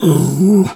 pgs/Assets/Audio/Animal_Impersonations/bear_pain_hurt_01.wav at 7452e70b8c5ad2f7daae623e1a952eb18c9caab4
bear_pain_hurt_01.wav